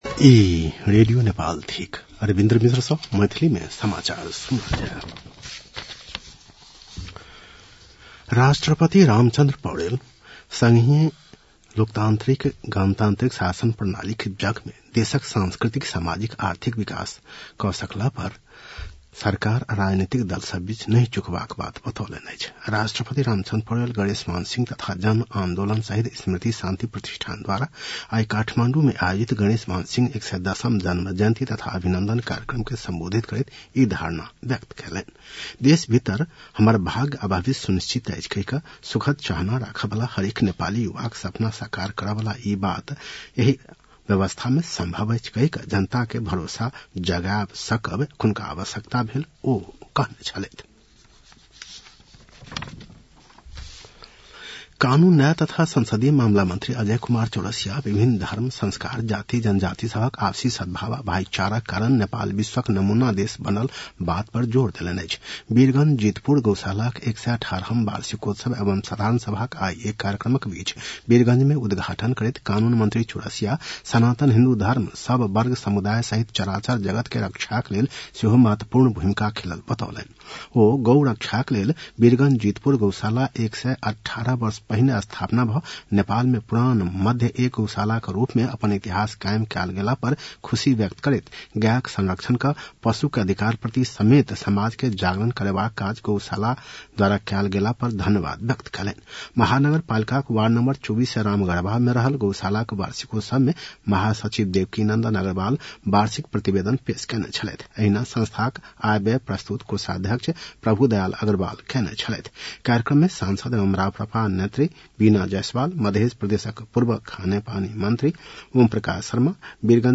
मैथिली भाषामा समाचार : २५ कार्तिक , २०८१